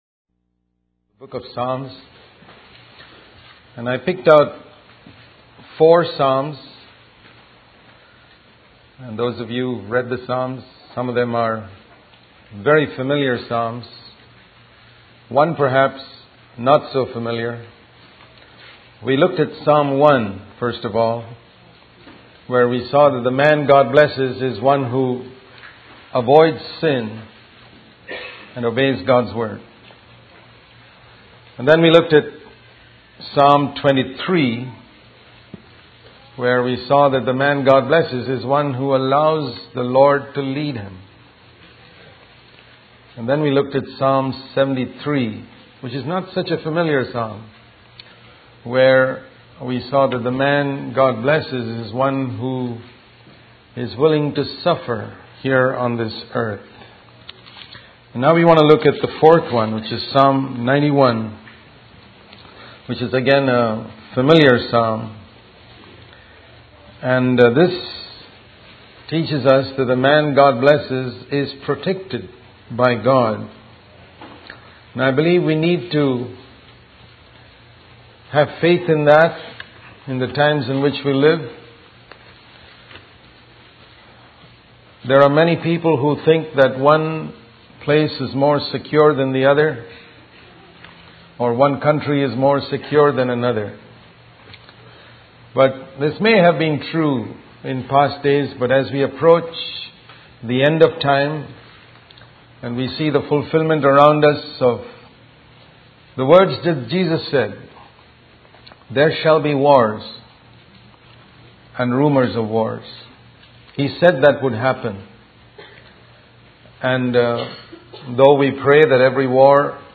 In this sermon, the preacher emphasizes the importance of having faith in God's promises. He shares a story about a man struggling with anger who continually seeks God's help but fails to overcome his temper.